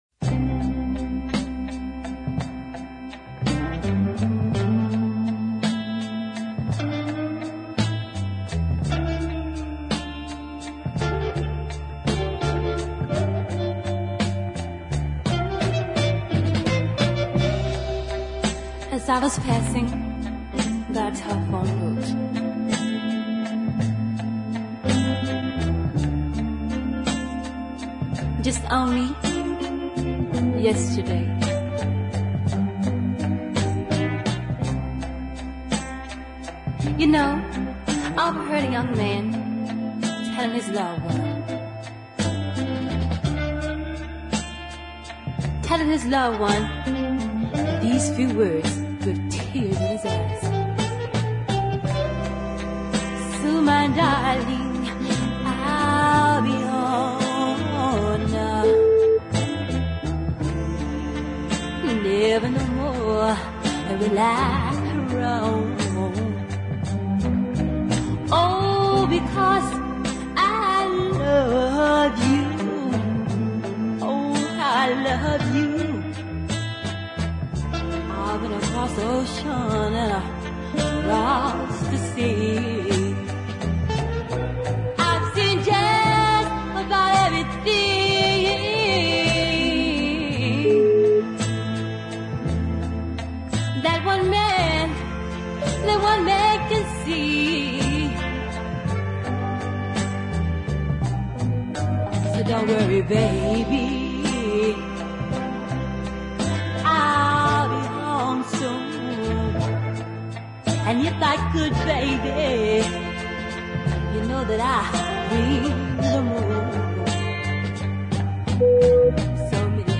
The tracks were recorded at a studio in Tupelo Mississippi.